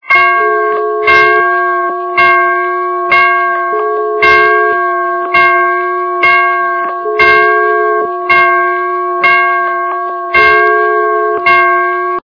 » Звуки » звуки для СМС » Звук дла СМС - Церковный колокол
При прослушивании Звук дла СМС - Церковный колокол качество понижено и присутствуют гудки.